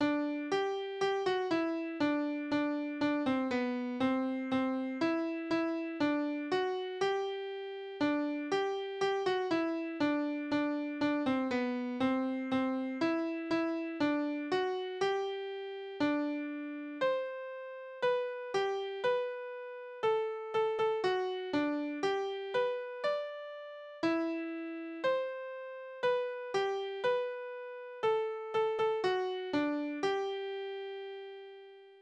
Politische Lieder:
Tonart: G-Dur
Taktart: 4/4
Tonumfang: kleine Dezime